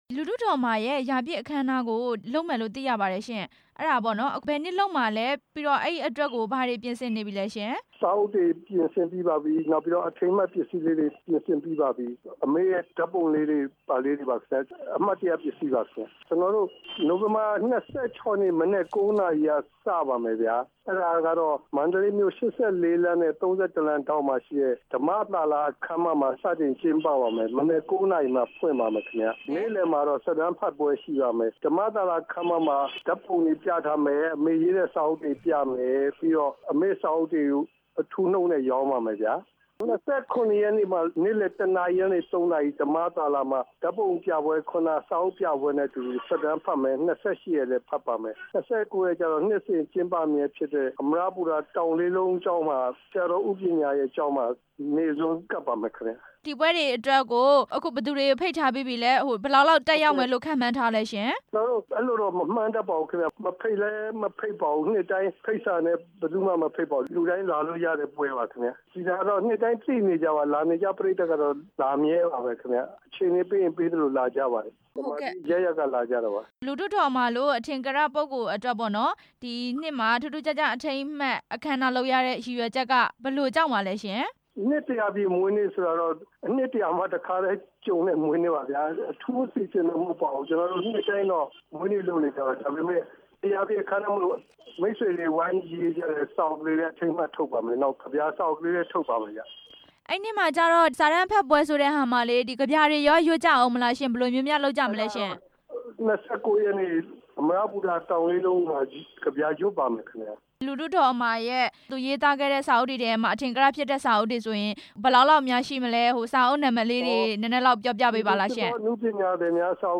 စာရေးဆရာ ဦးညီပုလေးနဲ့ မေးမြန်းချက်